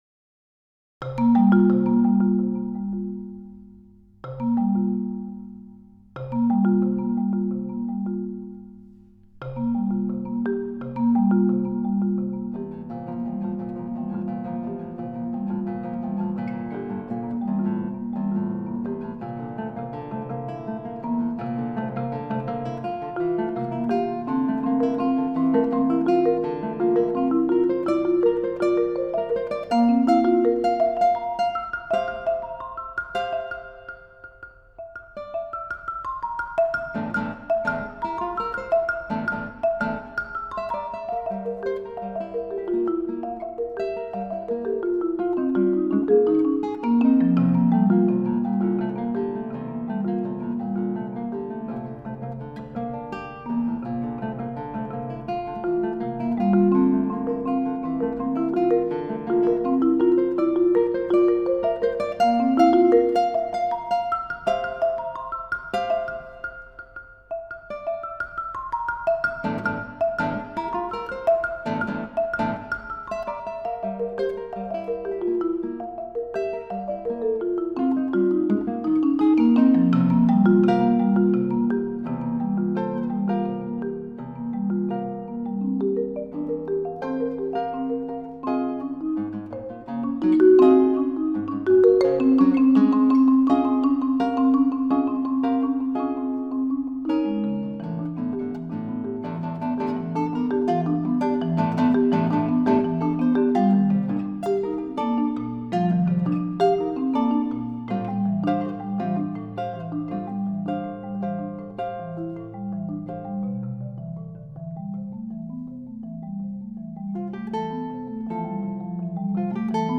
solo guitar CD
marimba